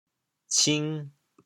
cing2读本调，“下”读轻声